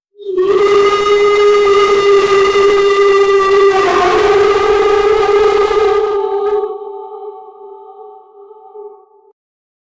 A Muffled sound, cry or something similliar, it is painfull and Disturbing.
a-muffled-sound-cry-or-l6uwsjkh.wav